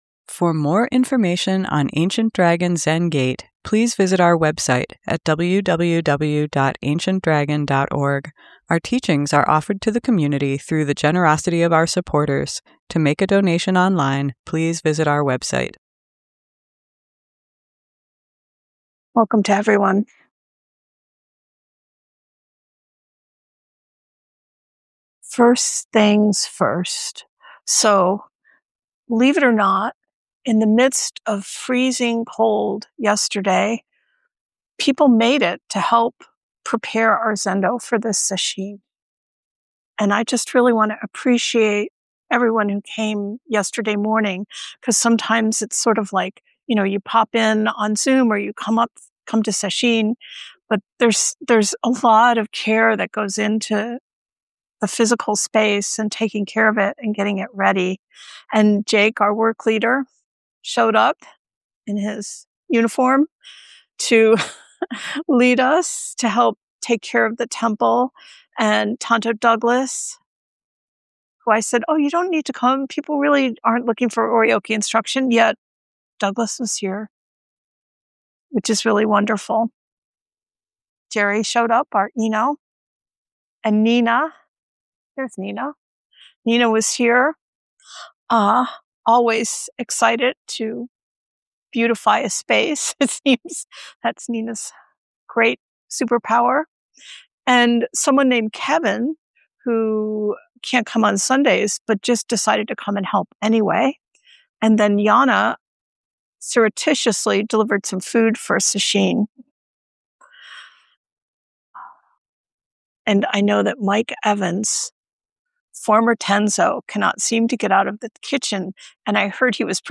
Sunday Morning Dharma Talk